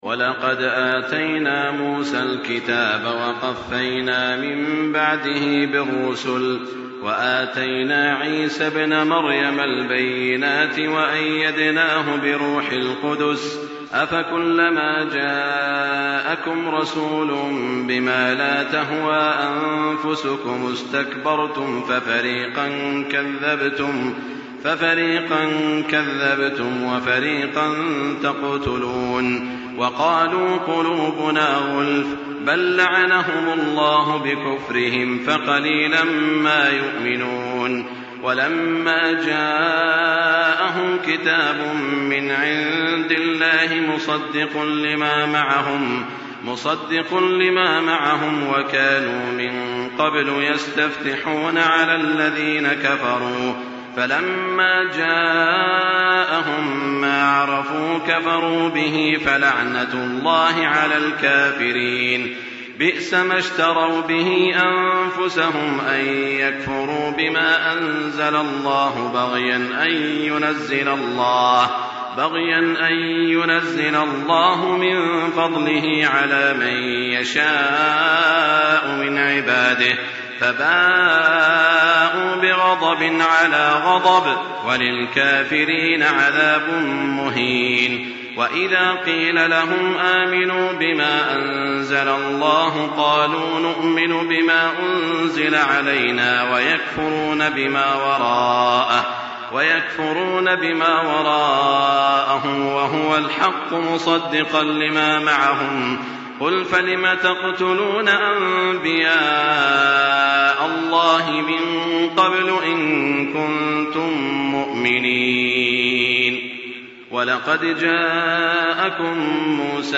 تراويح الليلة الأولى رمضان 1424هـ من سورة البقرة (87-157) Taraweeh 1st night Ramadan 1424H from Surah Al-Baqara > تراويح الحرم المكي عام 1424 🕋 > التراويح - تلاوات الحرمين